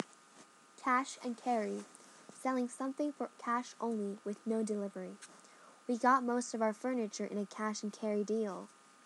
英語ネイティブによる発音は下記のURLから聞くことができます。